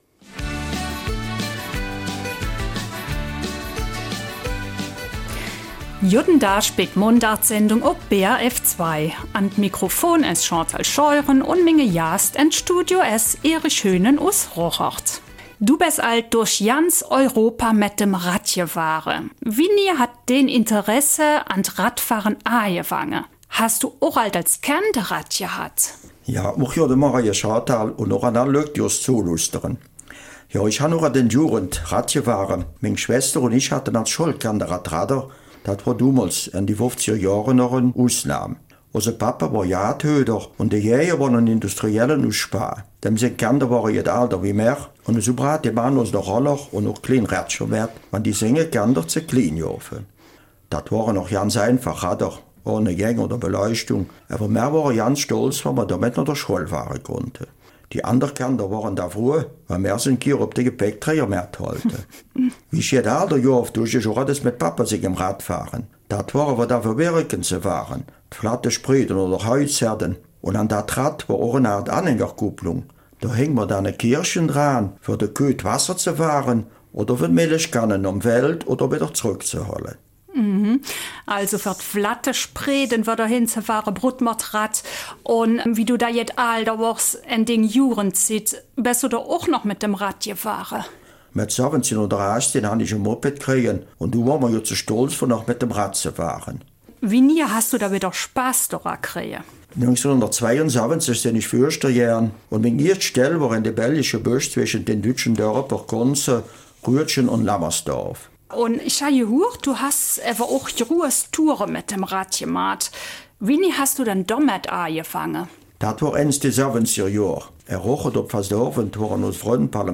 In der Mundart-Sendung spricht er unter anderem über seine Touren durch Holland, England, Frankreich und Bulgarien.